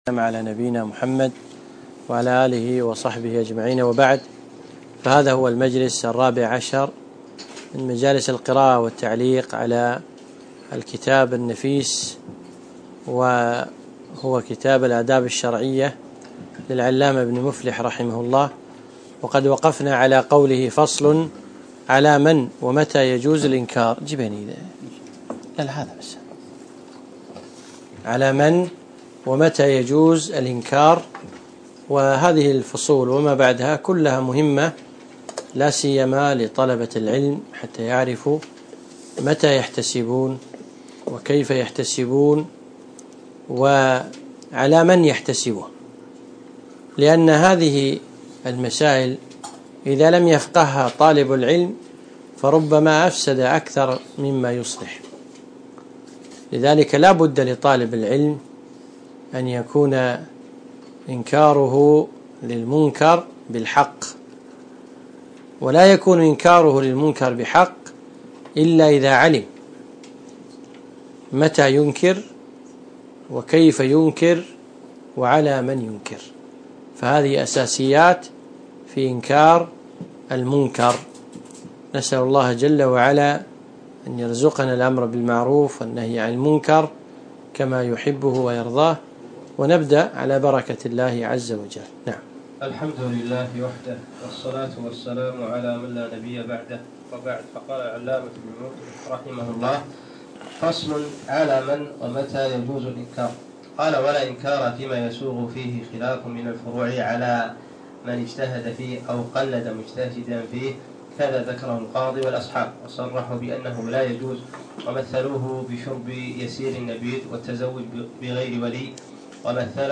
الدرس الرابع عشر